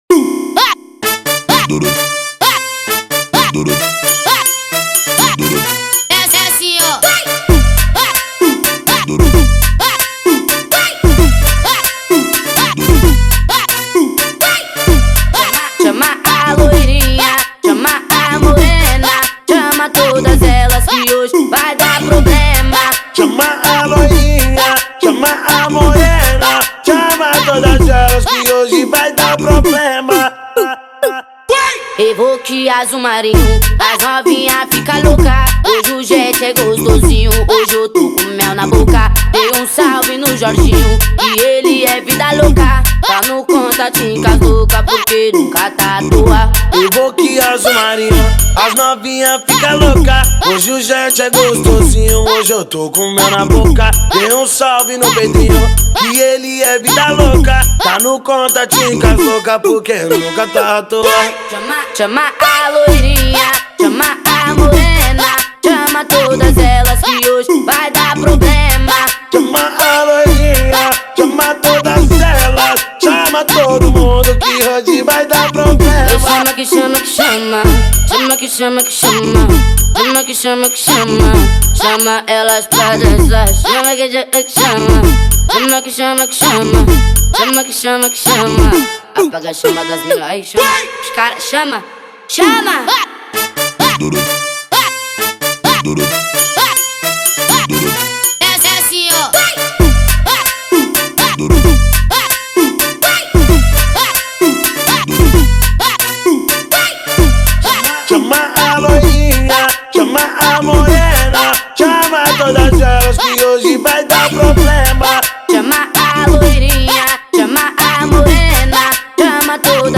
2025-03-10 23:47:39 Gênero: Funk Views